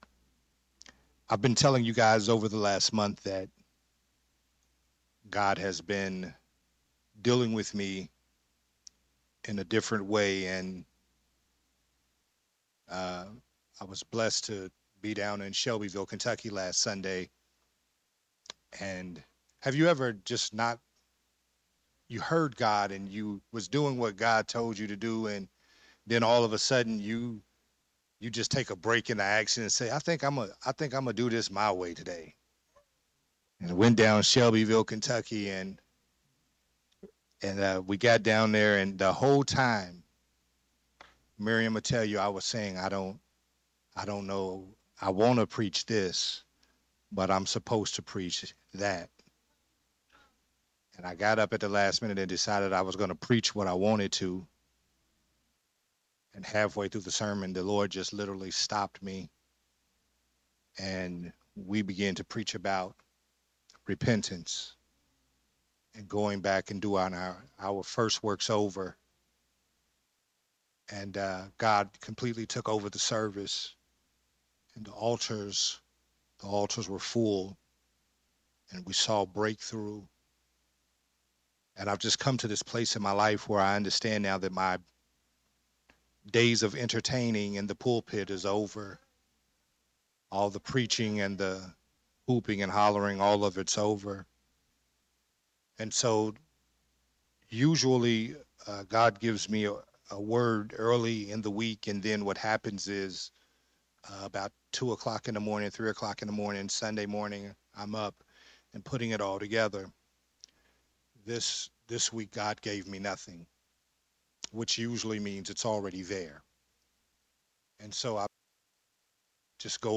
Part 4 of the sermon series “So Much On The Line”
recorded at Growth Temple Ministries on Sunday